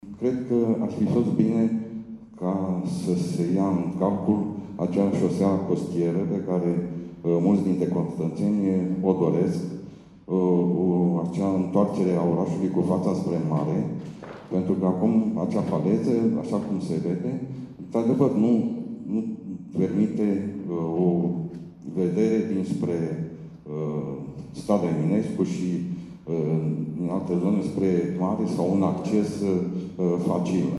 Sunt doar două din întrebările ridicate de participanții la dezbaterea publică a Planului Urbanistic Zonal al zonei centrale a municipiului Constanța.